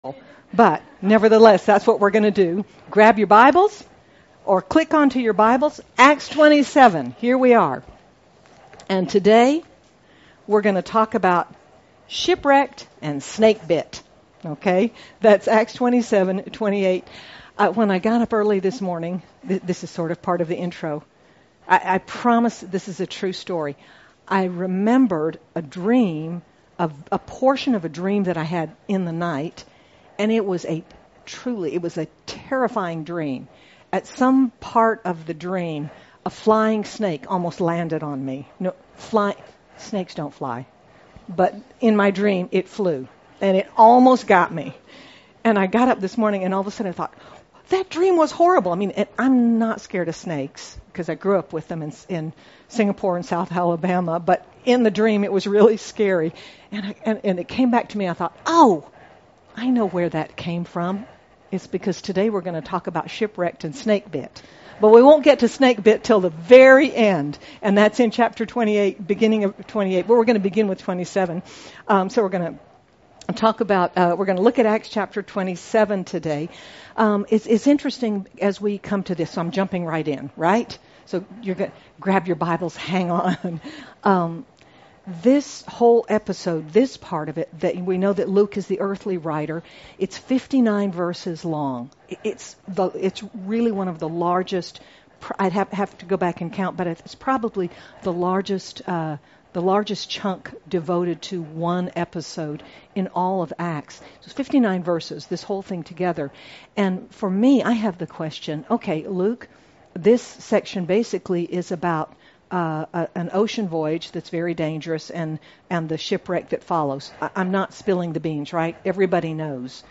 Sermon by